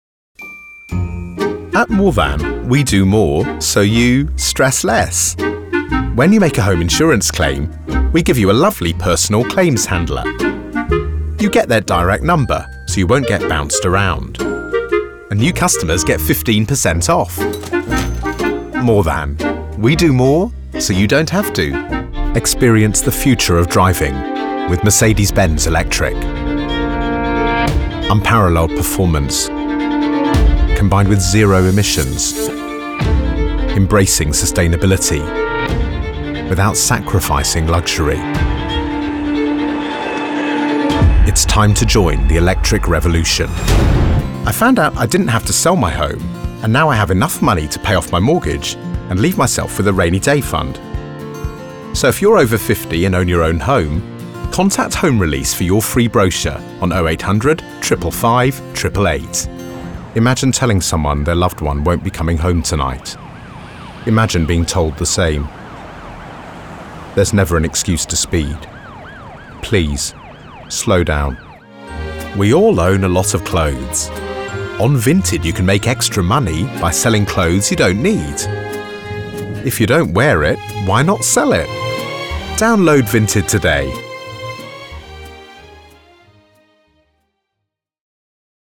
Commercial Demo
British RP
Middle Aged
AUTOMOTIVE & COMMERCIAL